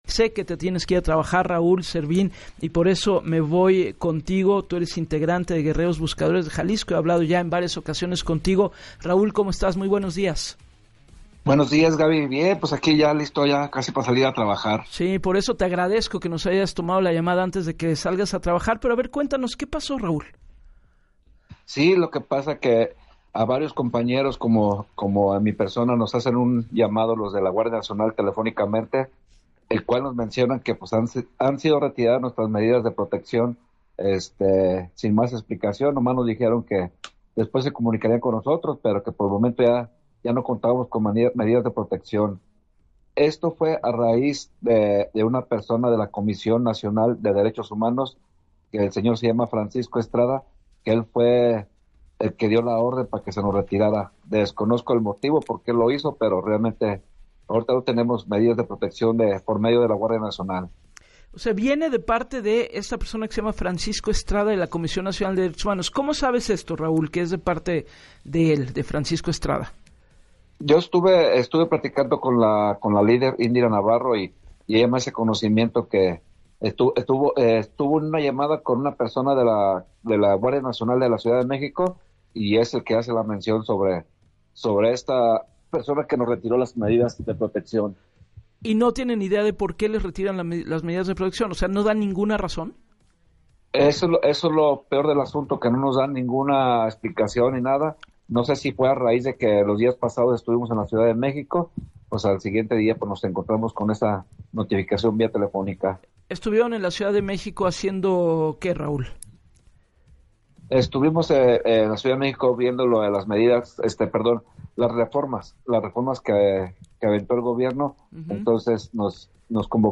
En entrevista para “Así las Cosas” con Gabriela Warkentin